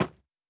SnowSteps_02.wav